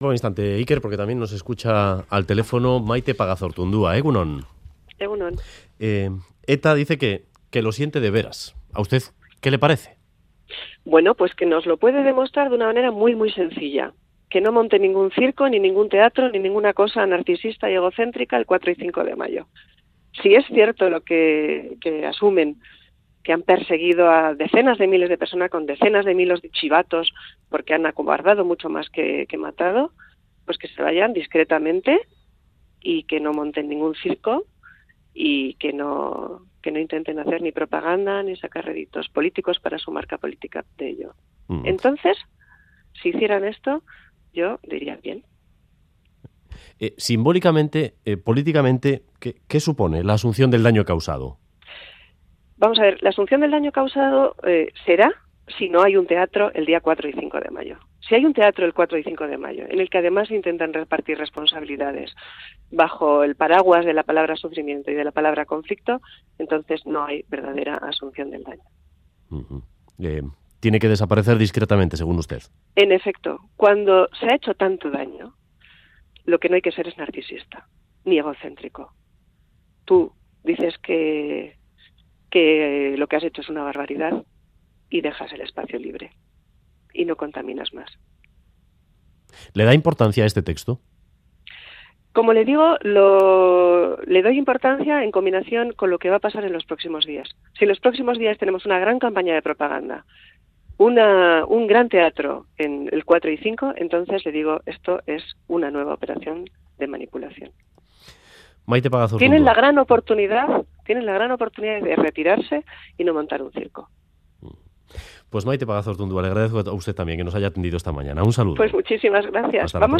Audio: Audio: Declaraciones de Maite Pagazaurtundua tras el comunicado de ETA